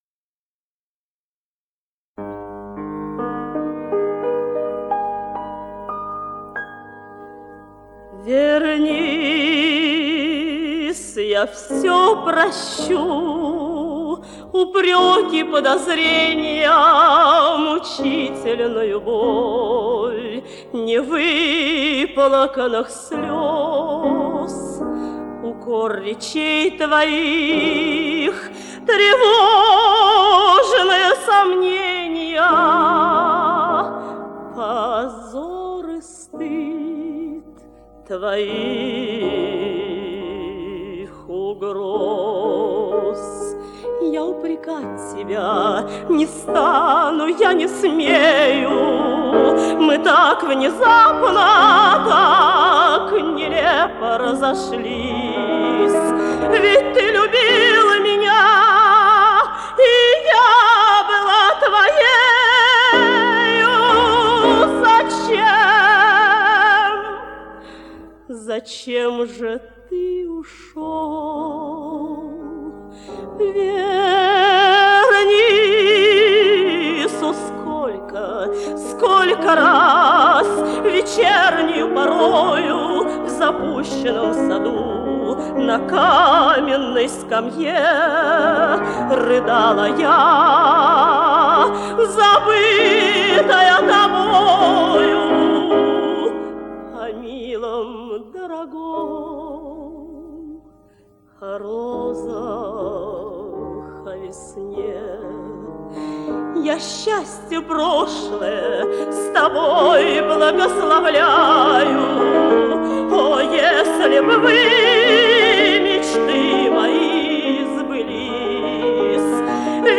Это русский романс, не цыганский...